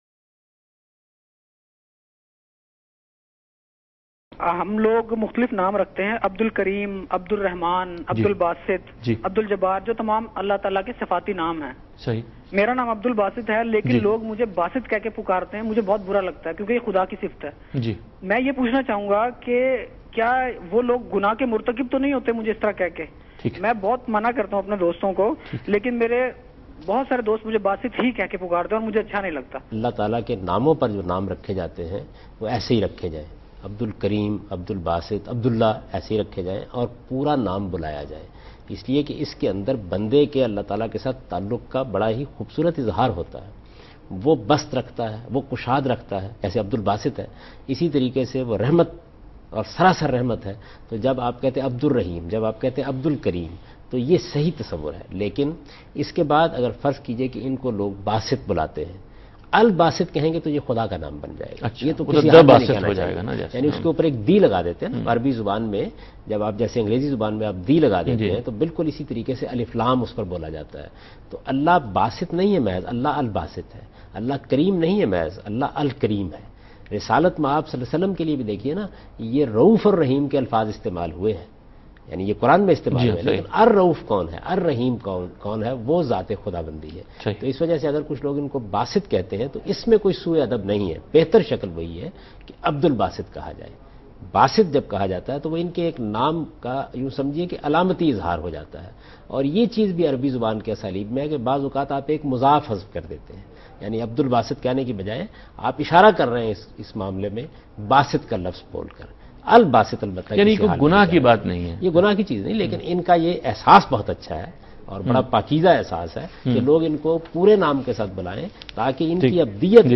Category: TV Programs / Dunya News / Deen-o-Daanish /
Javed Ahmad Ghamidi Answers a question in program Deen o Daanish on Dunya News about "Naming Children after Attributes of God".